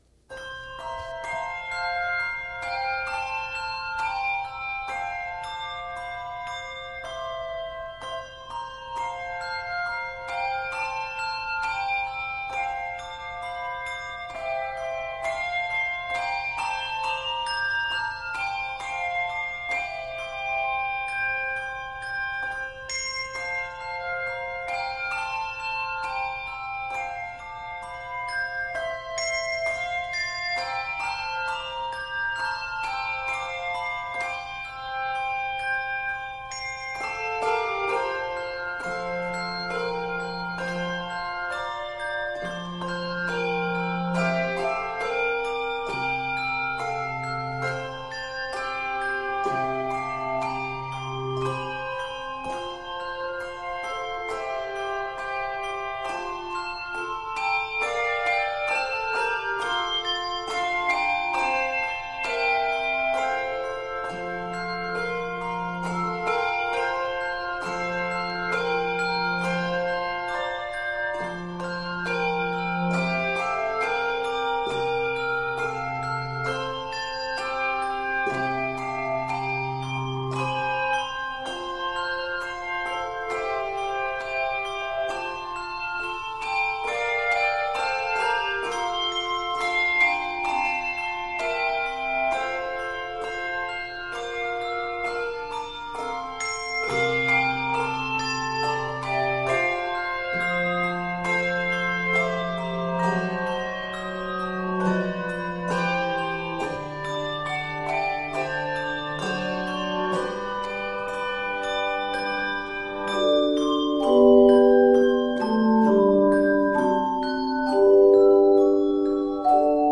Gentle and endearing
traditional German Christmas carol